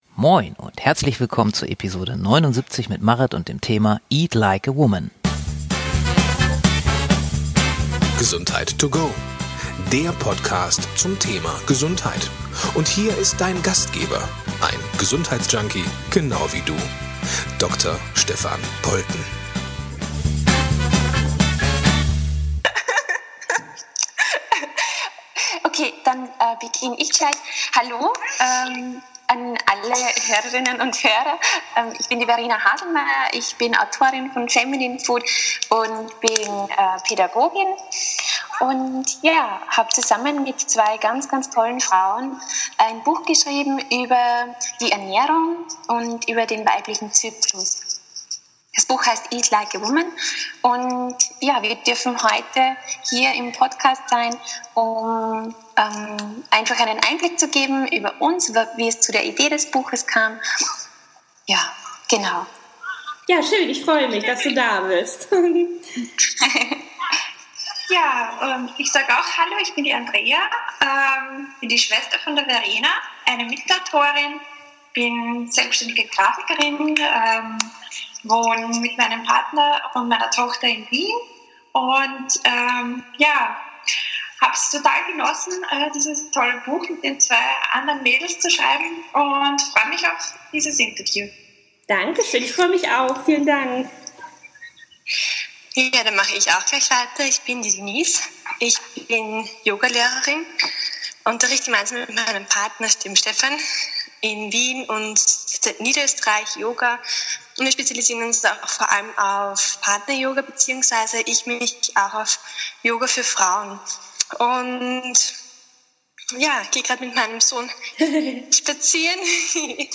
Das Interview hält eine Menge Inspiration bereit und fordert Frauen dazu auf, ihre Weiblichkeit zu feiern!